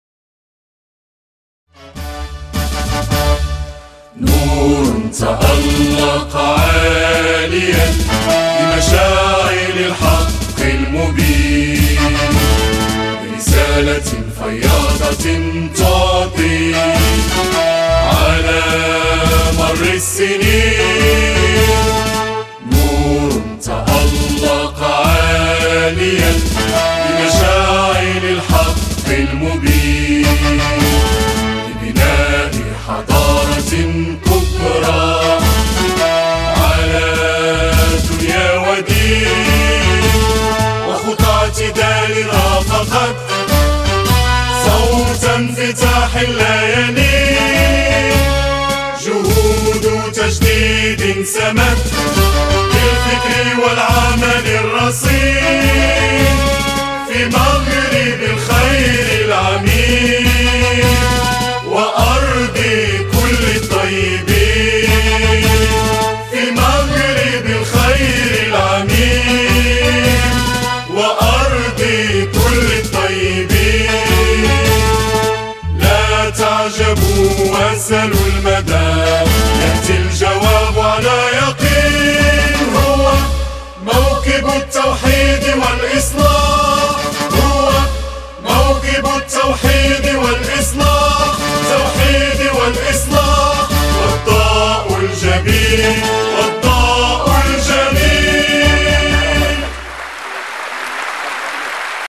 كلمات الأستاذ أحمد الصمدي/  ألحان الأستاذ لطفي بلحسن
نشيد-حركة-التوحيد-والإصلاح.mp3